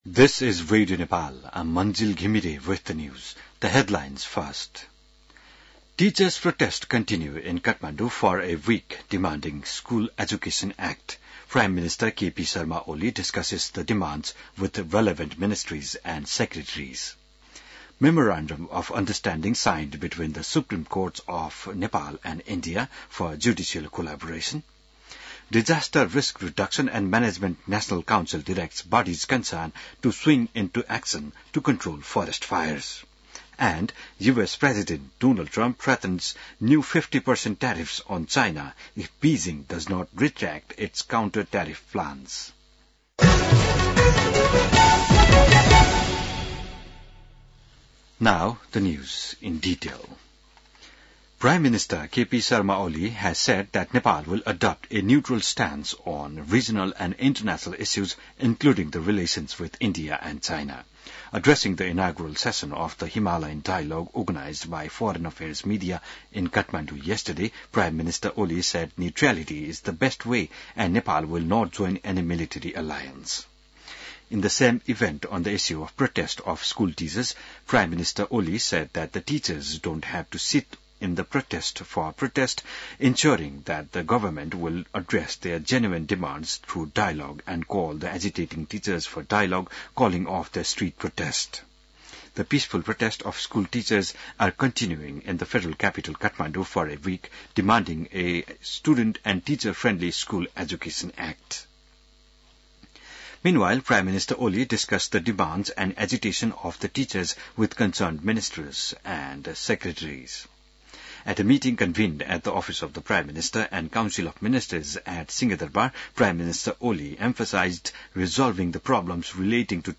बिहान ८ बजेको अङ्ग्रेजी समाचार : २६ चैत , २०८१